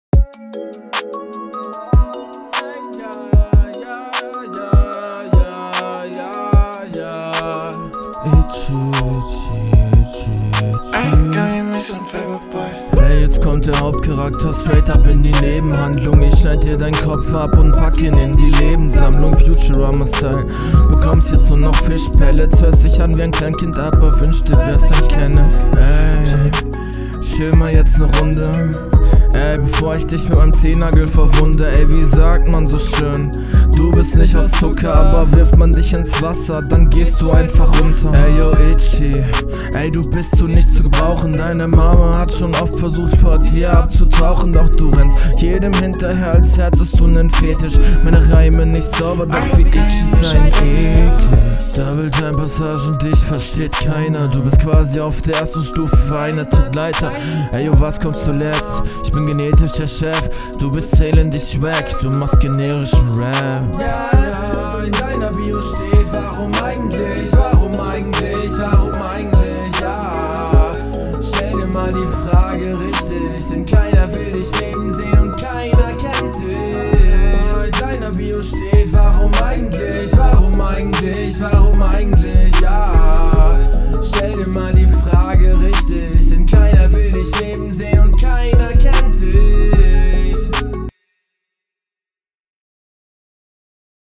Flow: Flow ansprechende Variationen.